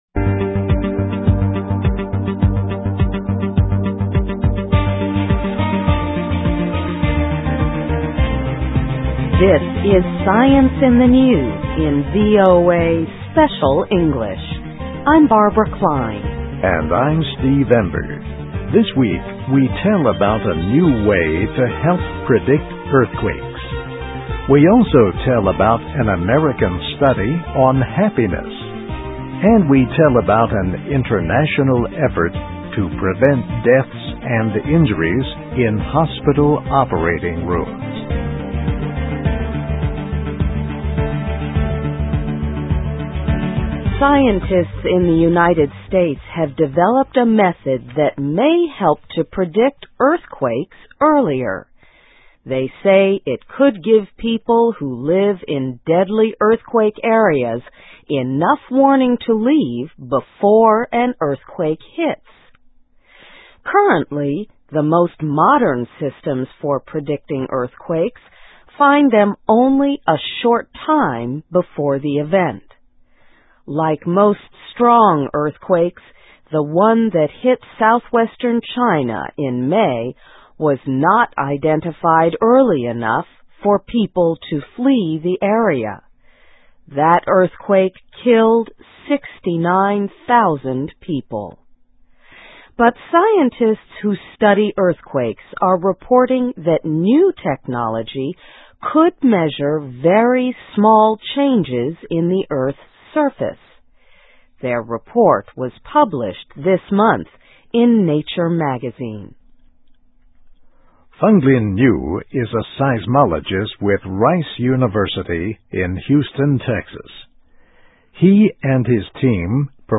A New Way to Help Predict Earthquakes (VOA Special English 2008-07-28)
ESL, EFL, English Listening Practice, Reading Practice